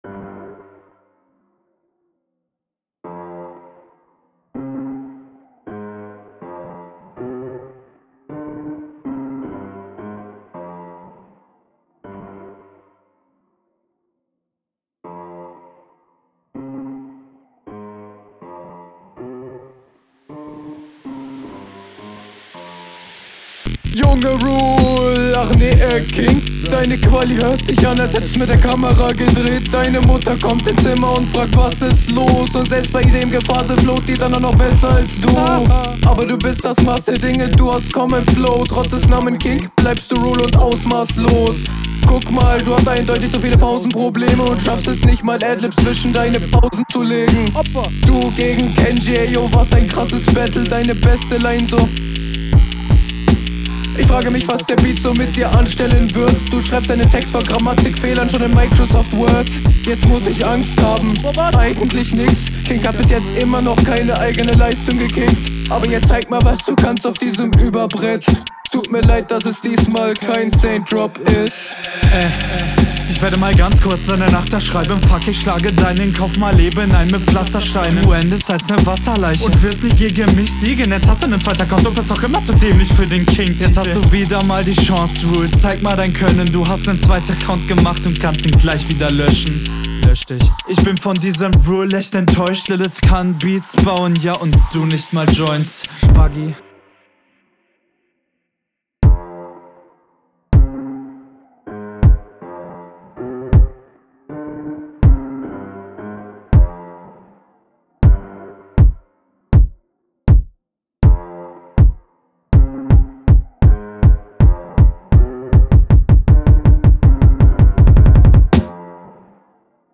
flow auch hier teilweise unsauber und holprig,, auch wenn es nicht unbedingt off beat ist. …
kann an der runde nicht so wirklich was aussetzen, stimme vllt bissl zu laut für …